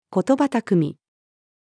言葉巧み-female.mp3